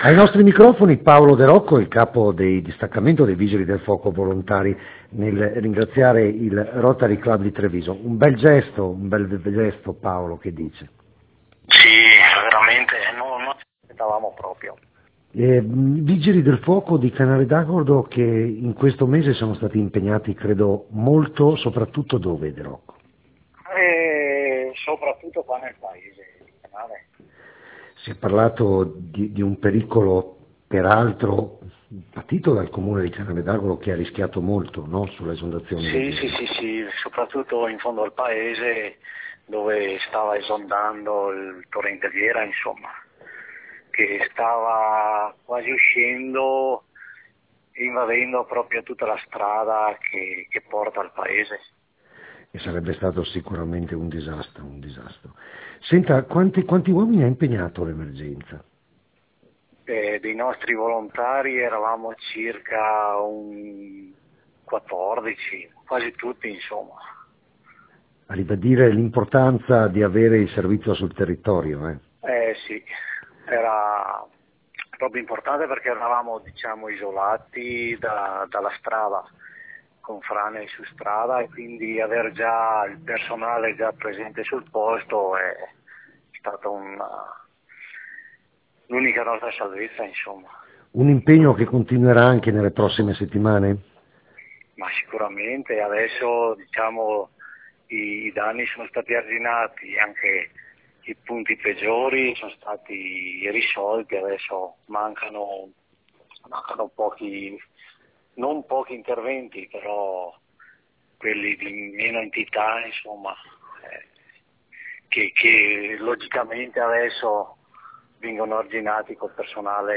Ai microfoni di Radio Più